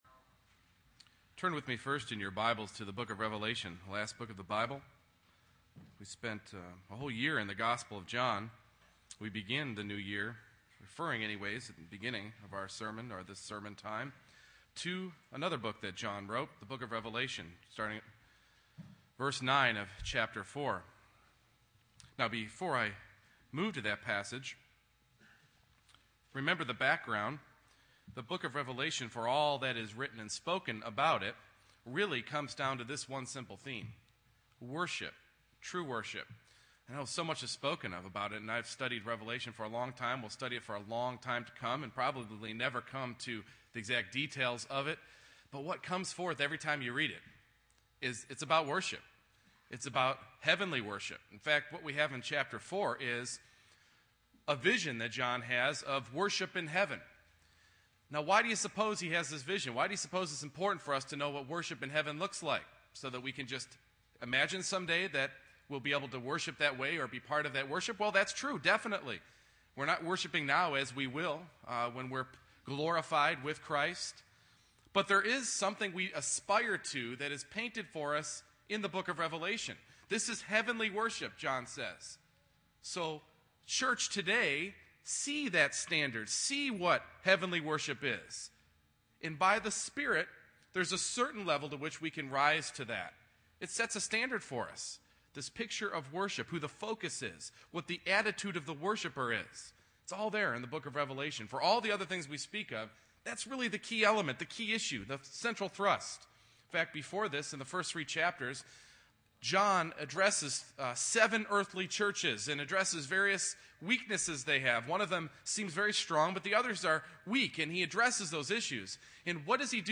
Redeemer Vision Passage: Revelation 4:9-11, Revelation 5:8-14 Service Type: Morning Worship